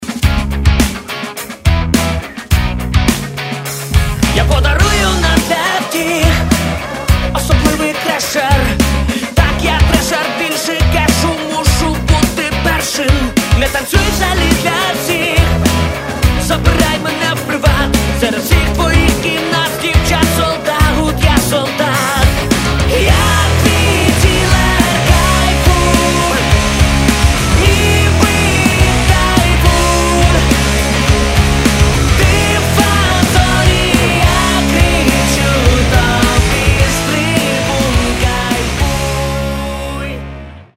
Каталог -> Рок и альтернатива -> Энергичный рок